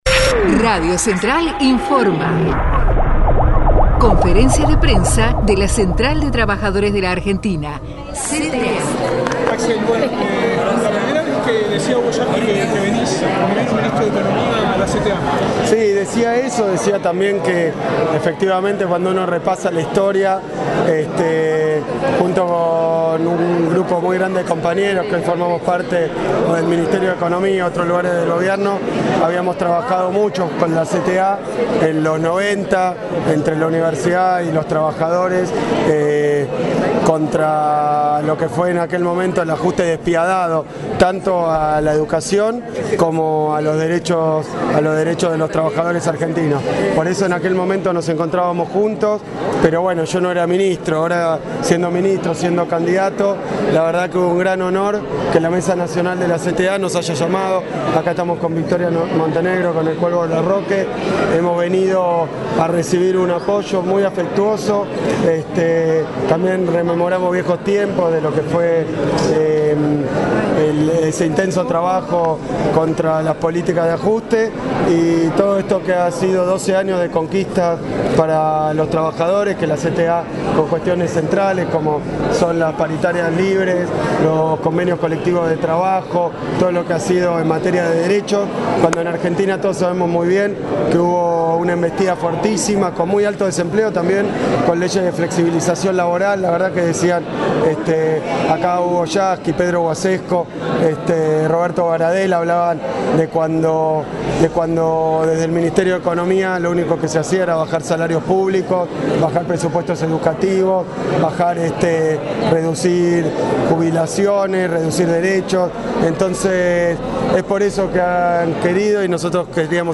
AXEL KICILLOF visitó la CTA // rueda de prensa